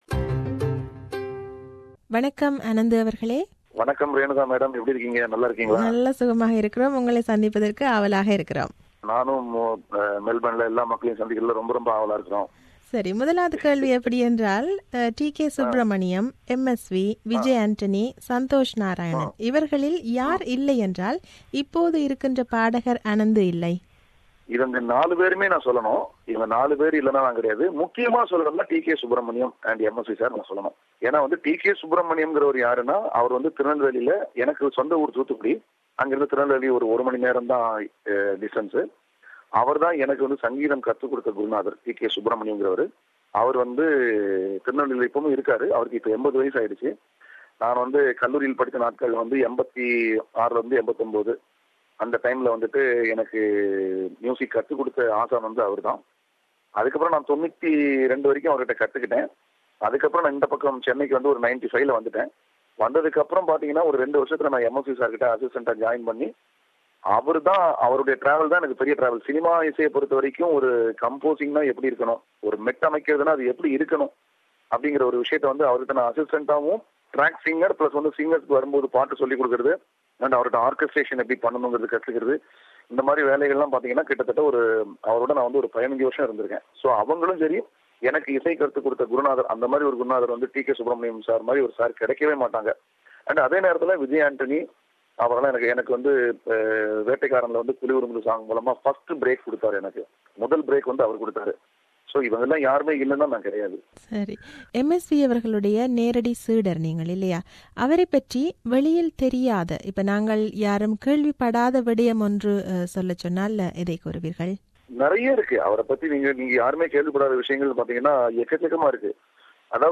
This is an interview with him.